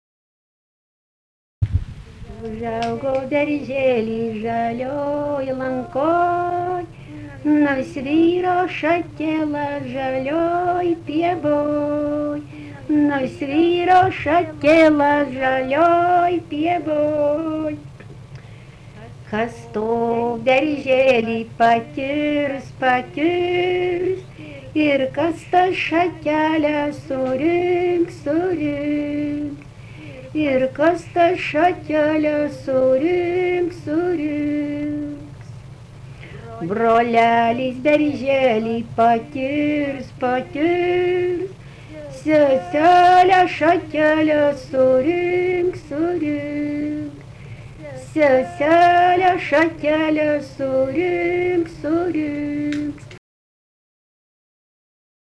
daina
Griauželiškiai
vokalinis